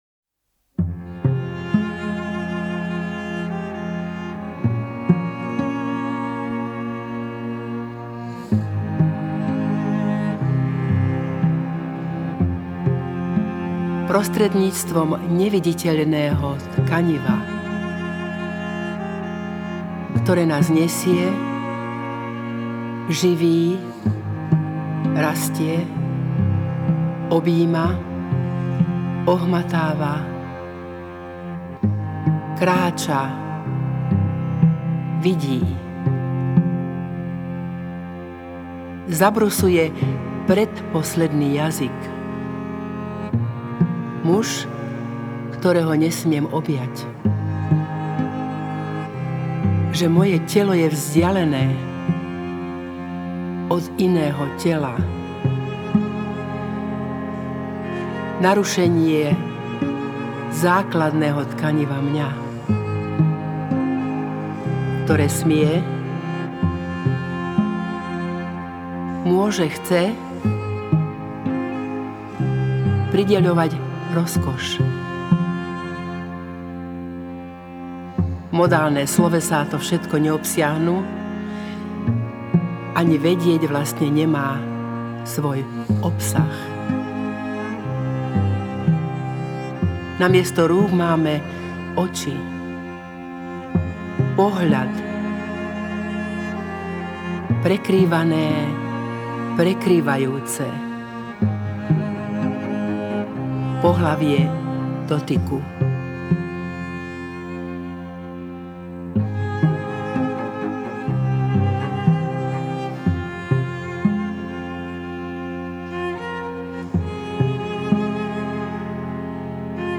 Violončelo
Bicie nástroje